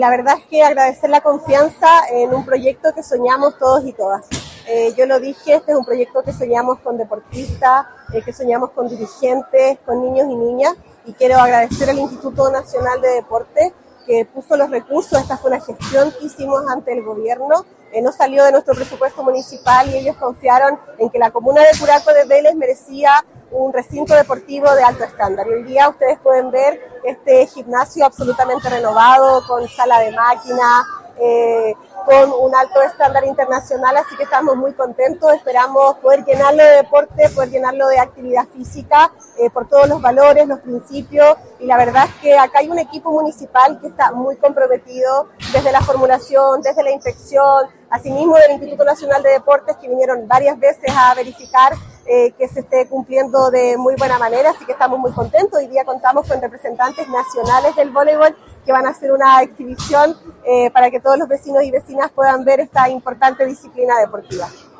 Por su parte, Javiera Yáñez, alcaldesa de Curaco de Vélez, agradeció los recursos dispuestos por el Gobierno de Chile, para materializar este importante proyecto para su comuna: